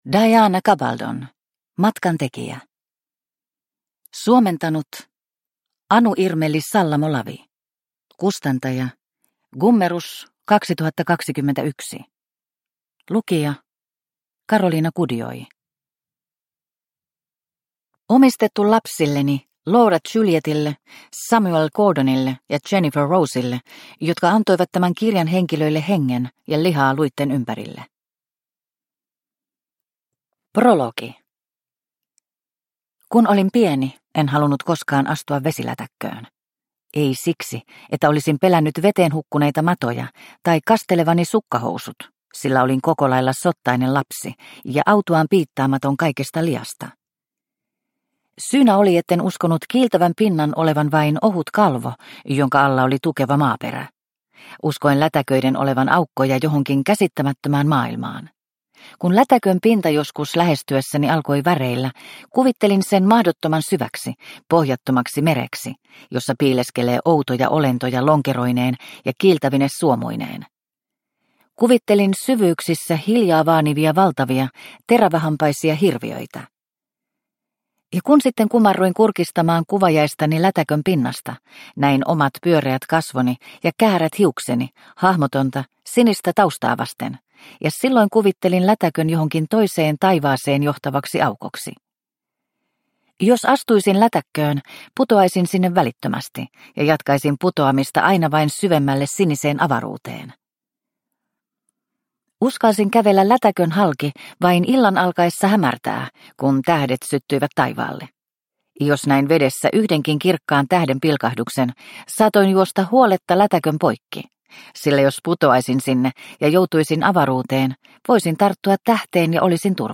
Matkantekijä – Ljudbok – Laddas ner